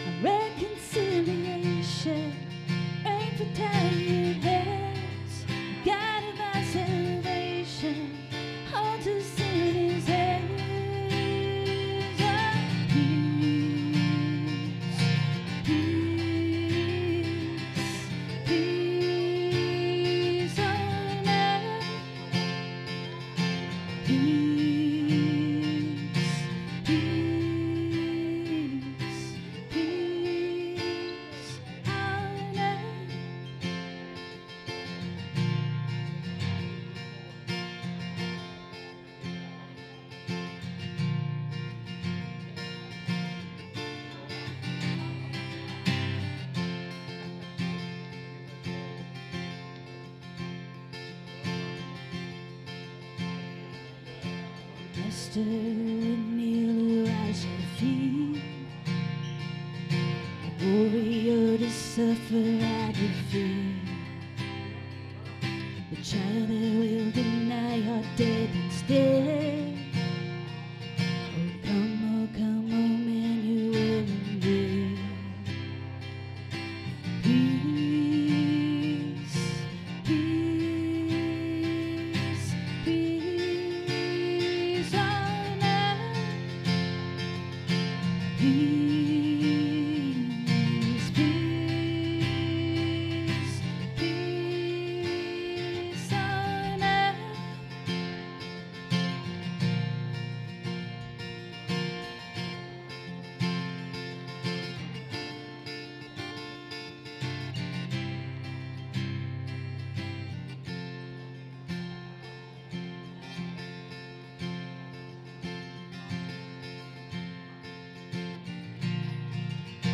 SERMON DESCRIPTION We live in a world that talks about peace, yet our hearts often feel anything but peaceful.